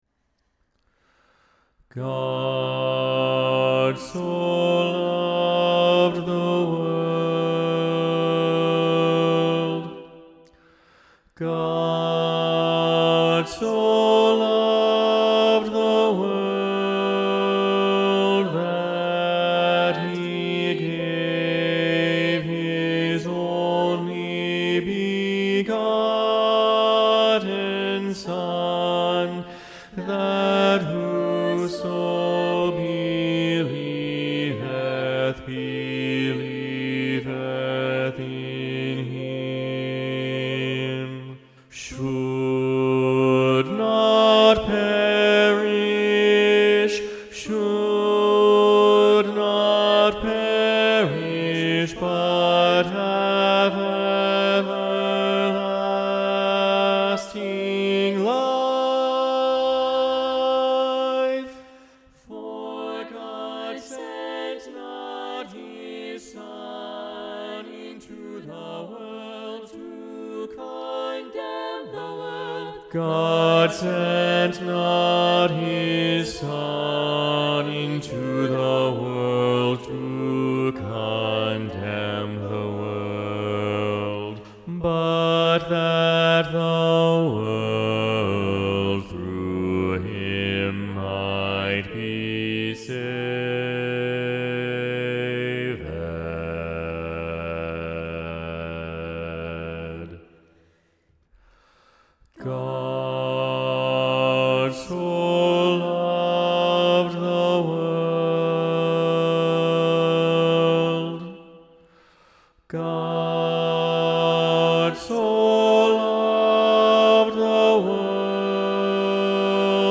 God so Loved the World SATB – Bass Predominant – John StainerDownload